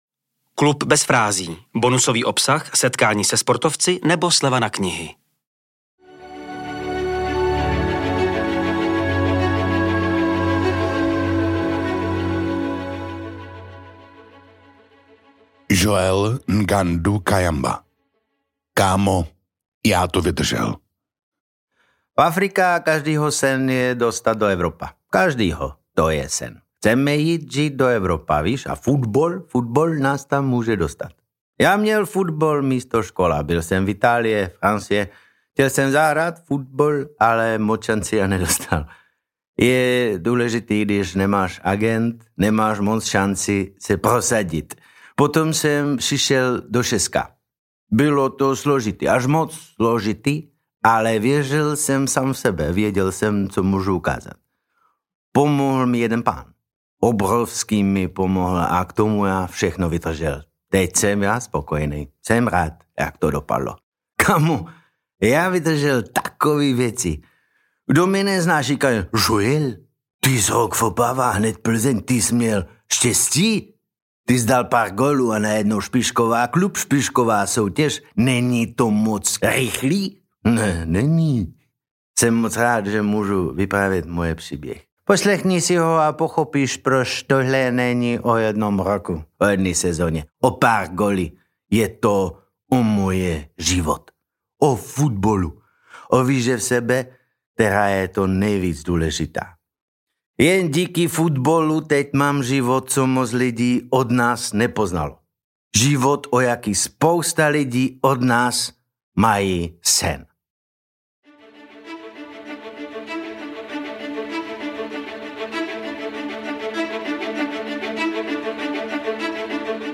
Teď si můžete jeho slova pustit namluvený skvělým hercem Davidem Novotným .